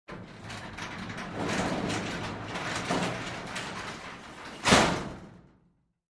Garage Door
fo_garagedr_sm_close_01_hpx
Large and small garage doors are opened and closed.